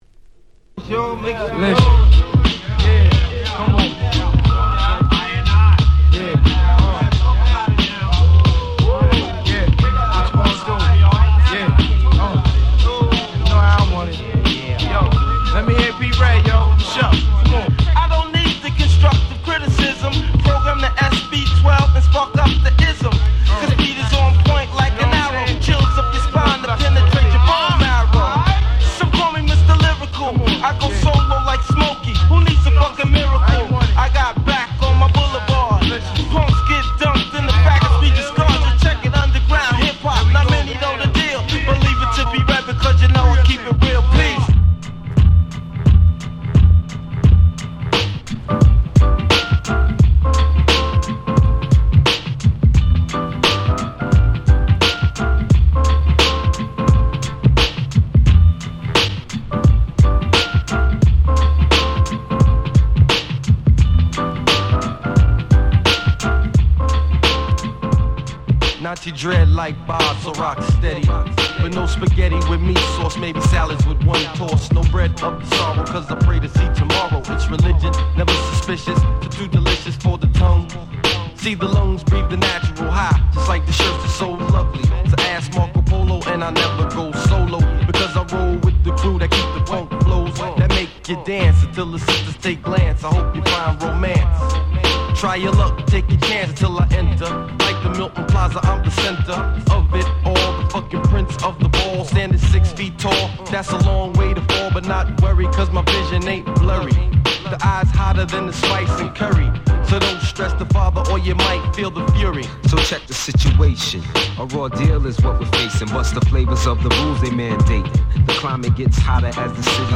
ピートロック Boom Bap ブーンバップ